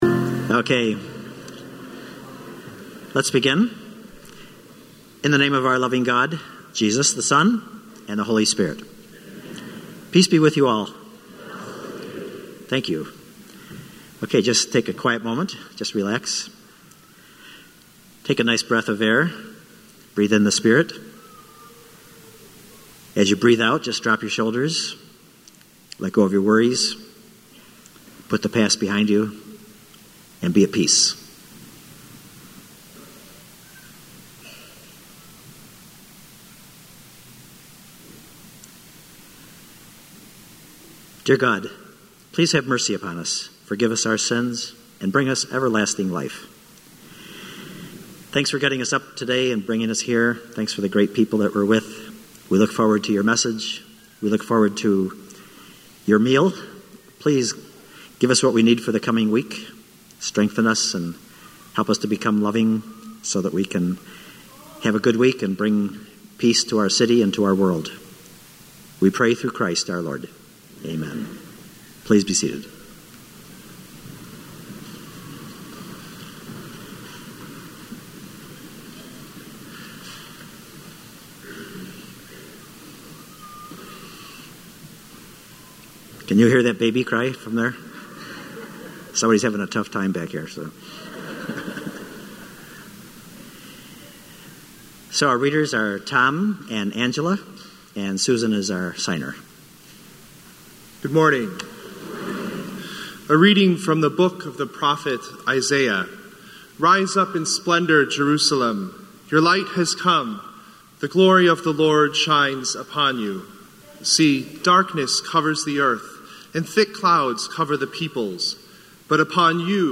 Spiritus-Christi-Mass-1.6.19-audio.mp3